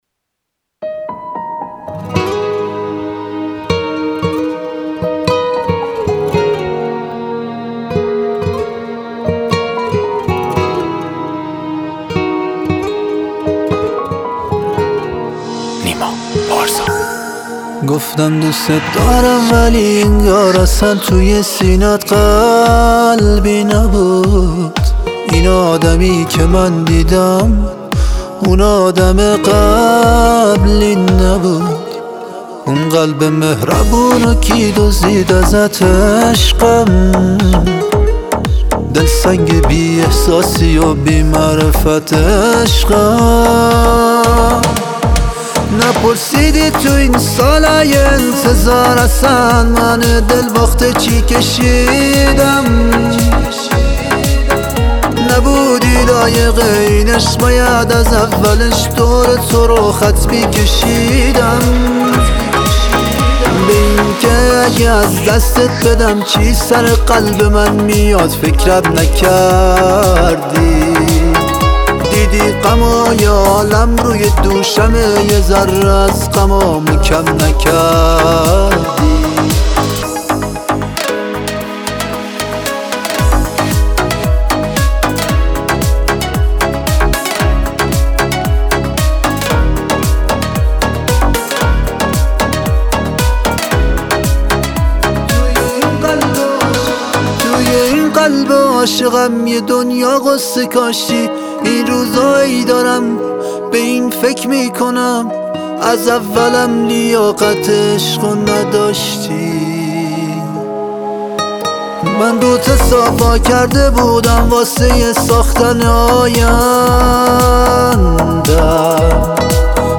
موزیک عاشقانه